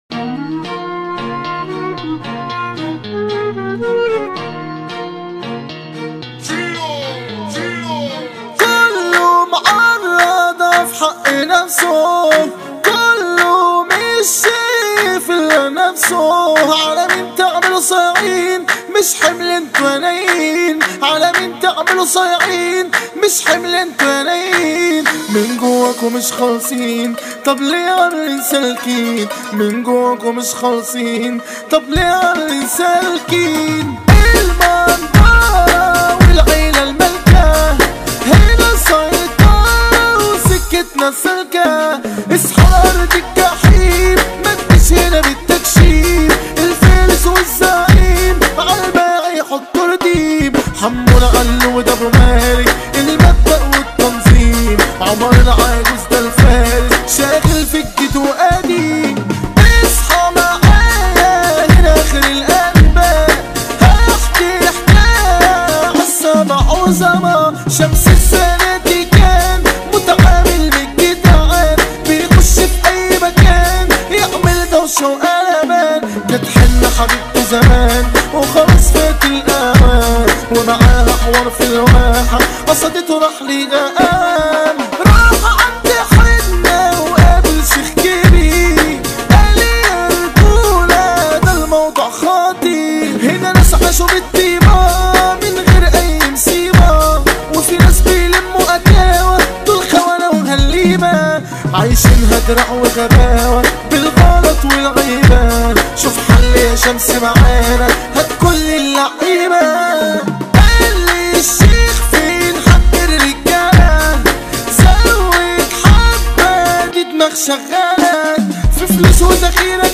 مهرجانات